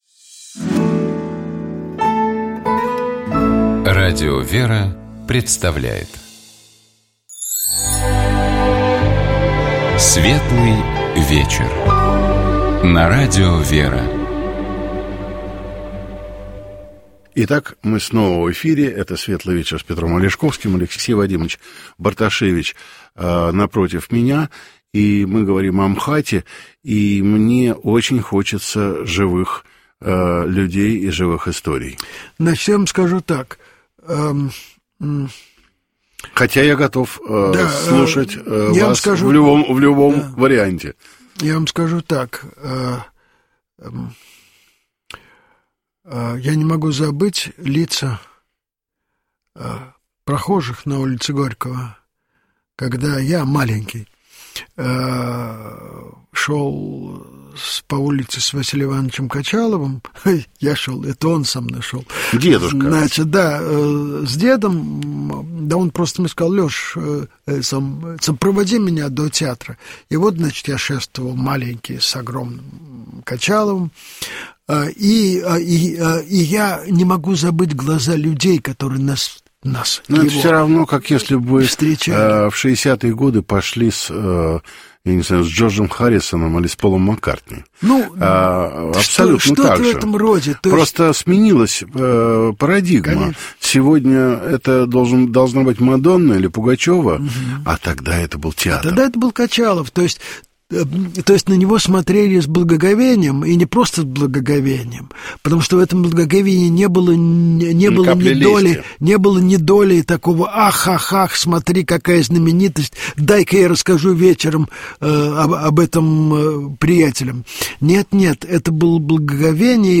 В гостях у Петра Алешковского был театровед, театральный критик, доктор искусствоведения, профессор, заслуженный деятель науки Российской Федерации